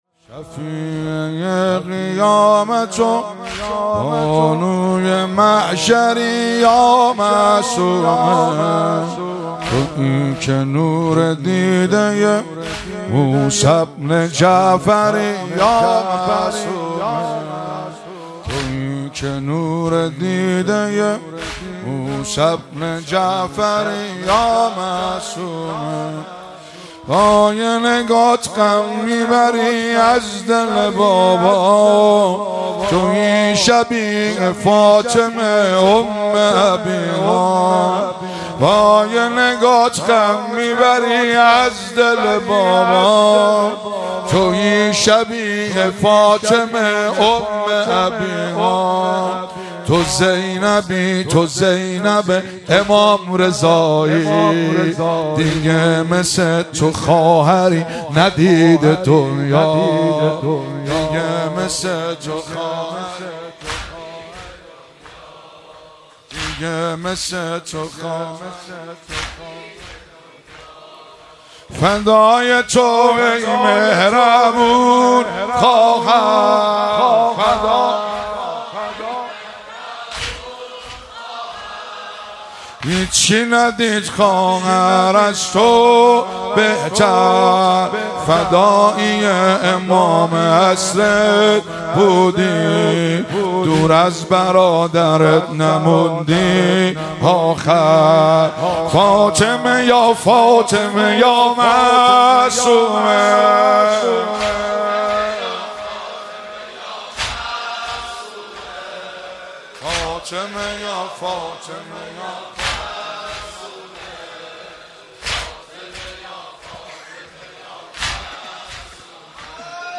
مراسم عزاداری وفات حضرت فاطمه معصومه (س)- آبان 1401
مراسم عزاداری وفات حضرت فاطمه معصومه (س)- آبان 1401 دانلود عنوان واحد- شفیعۀ قیامت و، بانوی محشری، یا معصومه تاریخ اجرا 1401-08-12 اجرا کننده مناسبت موضوع حضرت معصومه(س) نوع صوت مداحی مدت زمان صوت 00:03:52